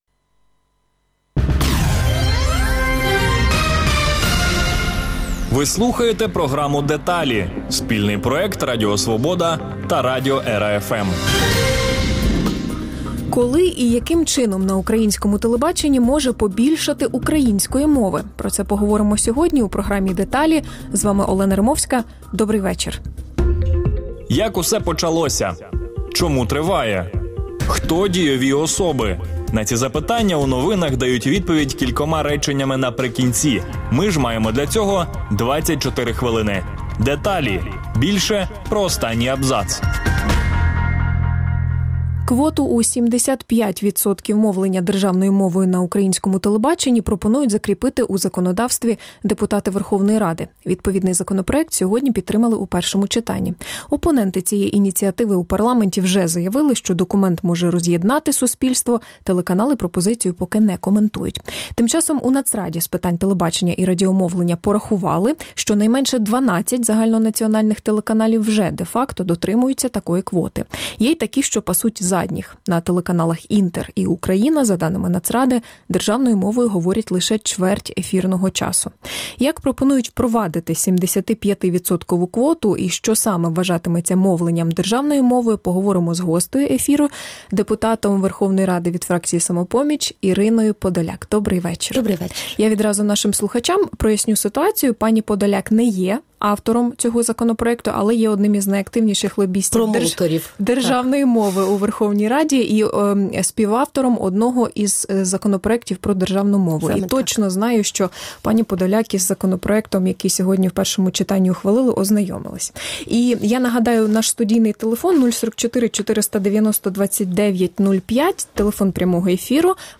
Гість: Ірина Подоляк, депутат Верховної Ради (Самопоміч)